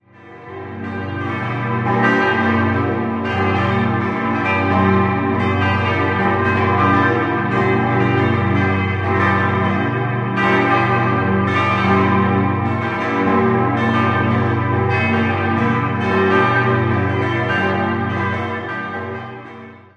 5-stimmiges erweitertes Salve-Regina-Geläute: g°-h°-d'-e'-g' Die kleine Glocke wurde 1972 von der Gießerei Zollinger in Rickenbach gegossen, die vier großen stammen aus demselben Jahr von der Firma Eijsbouts in Asten (Niederlande).